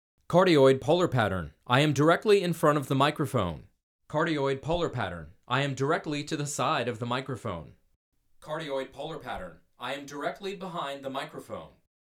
CARDIOID
Have a listen to the clip beneath and note the excellent rejection as I move behind the microphone.
vocals-cardioid-waveinformer.mp3